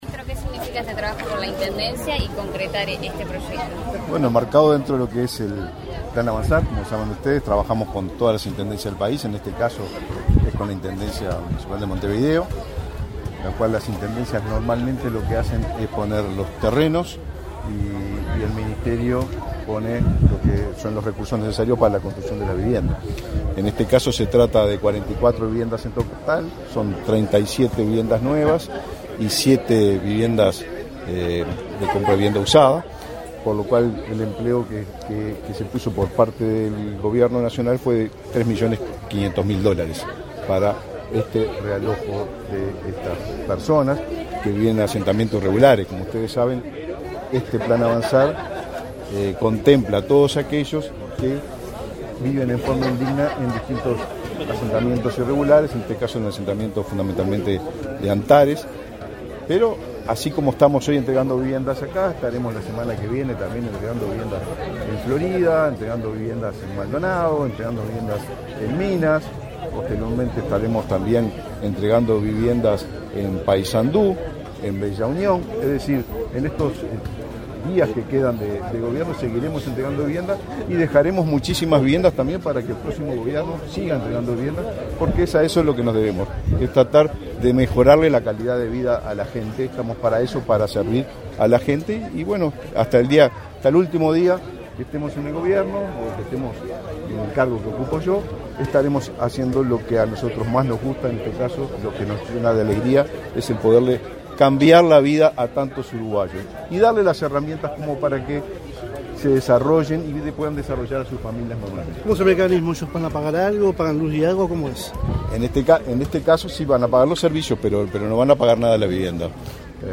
Declaraciones a la prensa del ministro de Vivienda y Ordenamiento Territorial, Raúl Lozano
Tras participar, este 13 de febrero, en la entrega de 37 viviendas para el realojo de familias que vivían en el asentamiento Antares, en Montevideo,
lozano prensa.mp3